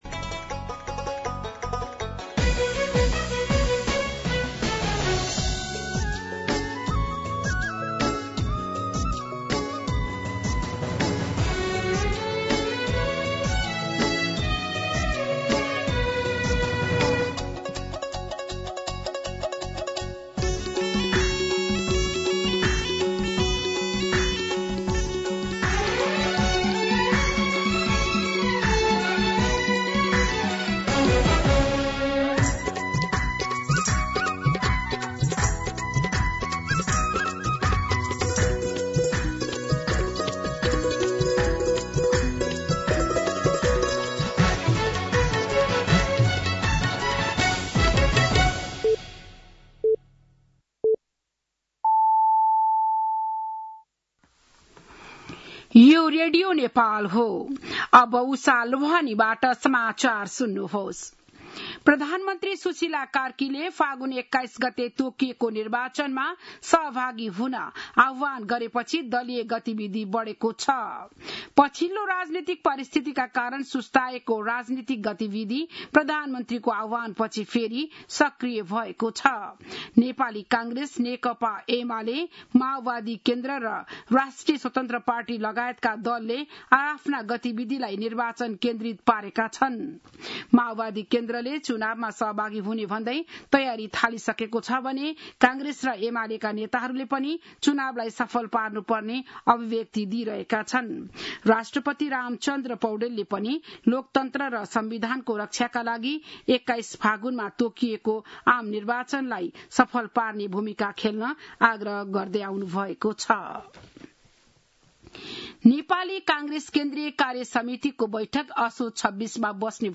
बिहान ११ बजेको नेपाली समाचार : १२ असोज , २०८२
11-am-Nepali-News-6-12.mp3